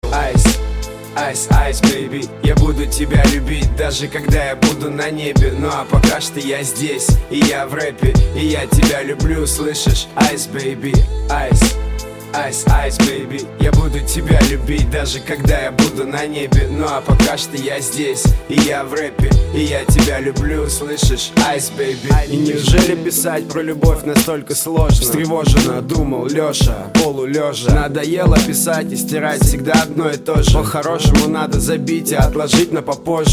Рэп, Хип-Хоп, R'n'B